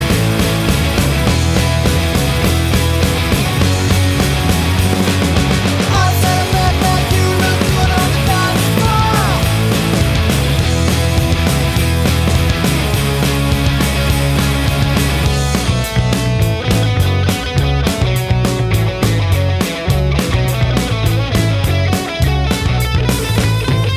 No Lead Guitar Indie / Alternative 2:52 Buy £1.50